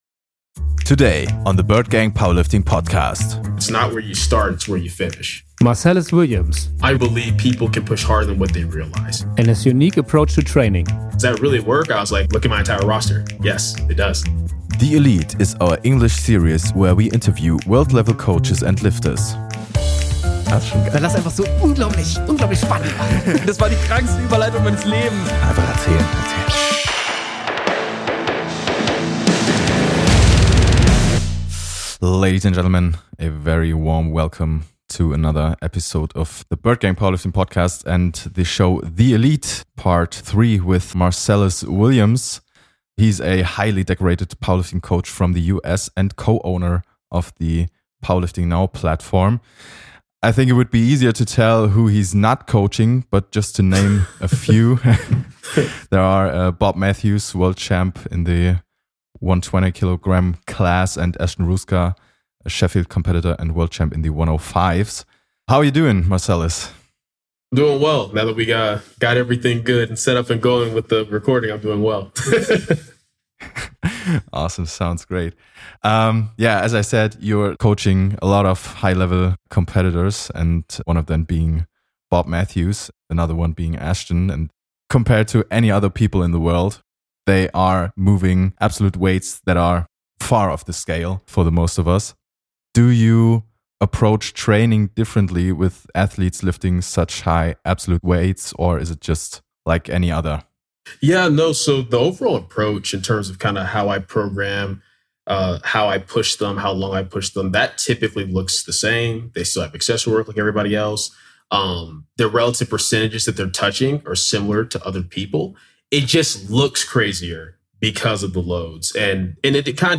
THE ELITE is our english series where we interview world level coaches and lifters.